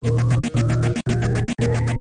UFO_Pickup.mp3